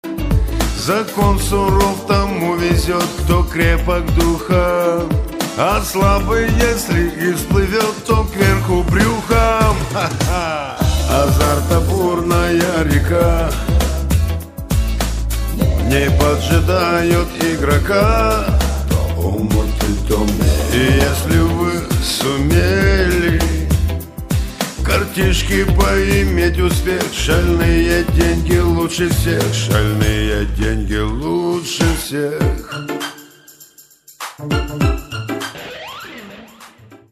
громкие
русский шансон
блатные